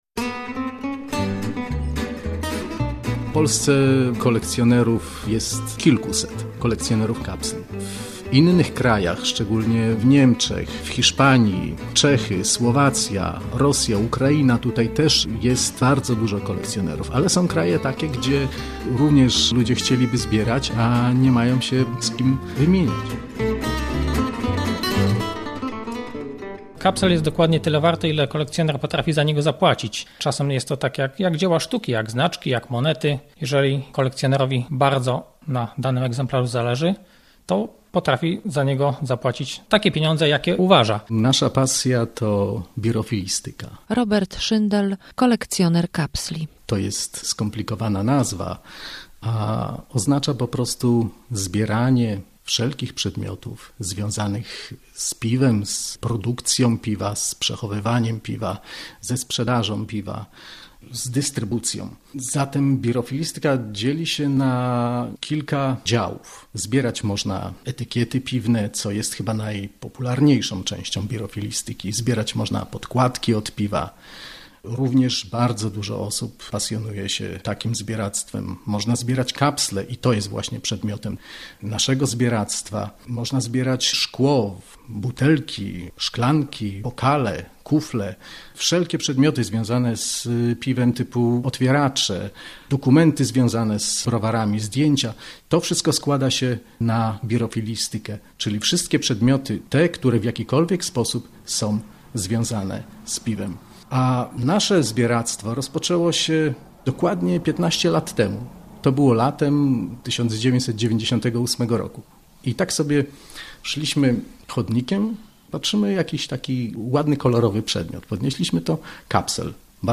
reportaz-kapsle.mp3